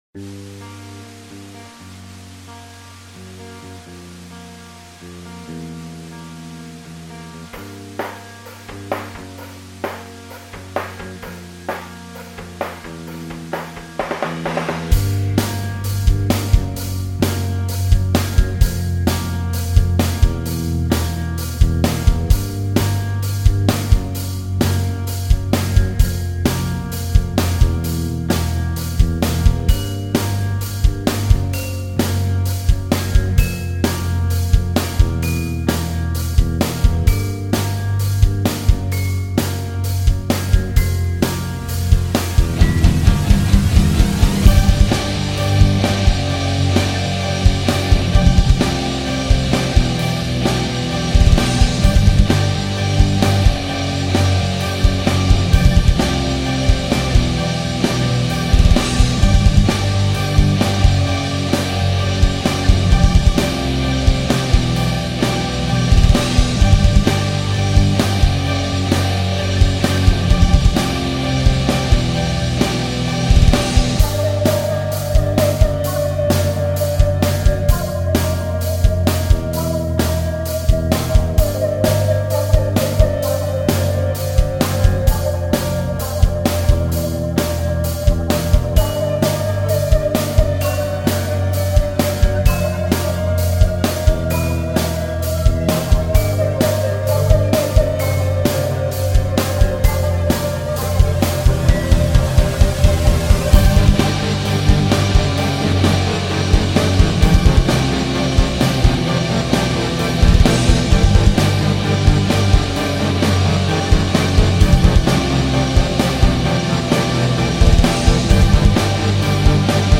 I felt that although all the other discord themes I heard were good, I didn't feel like any of them really capture the chaos and goofiness that comes along with his creepiness so I gave it a shot myself.
I just bought a ton of new software/gear for music production and this is my very first try at recording anything other then jam sessions at my buds house so please be kind.